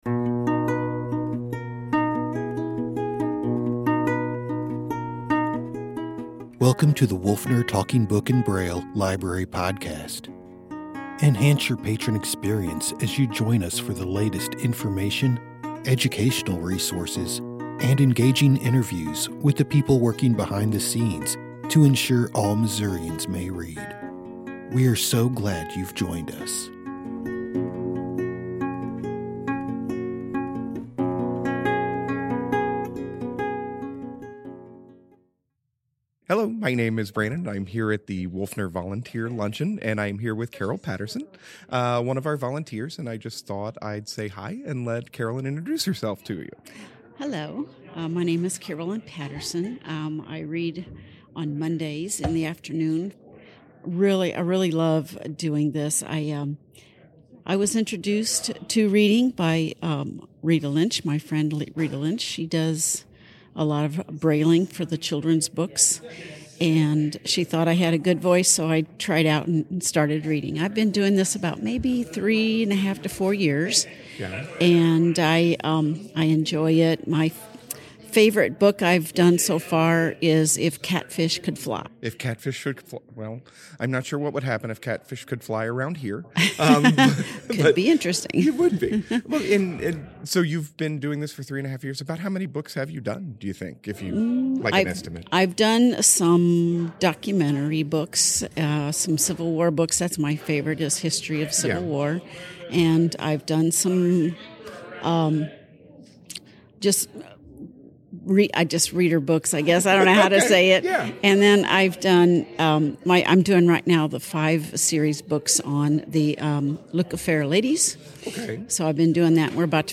Latest Wolfner Podcasts Wolfner Talking Book and Braille Library is now hosting a podcast on various topics! The podcast includes interviews with staff, volunteers, and authors.